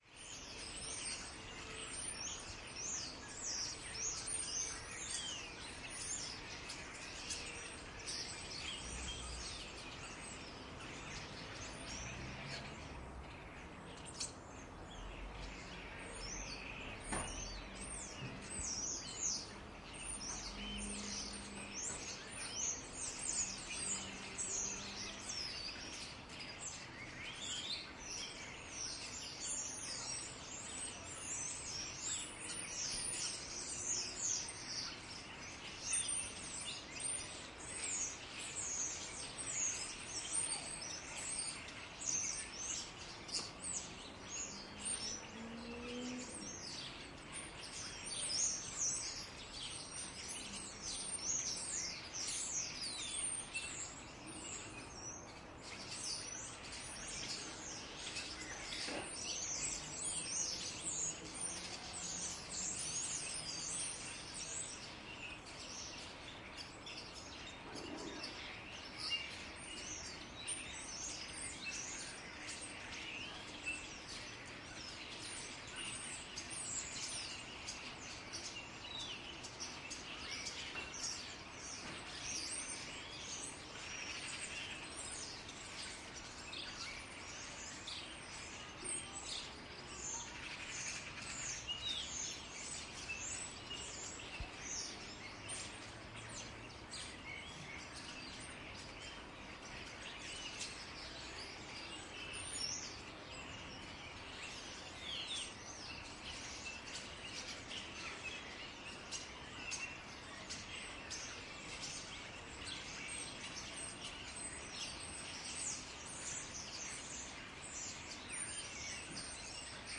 现场录音" kaak
描述：一只家鸦在栖息地叫着。
Tag: 加尔各答 清晨 乌鸦 环境音 现场录音 城市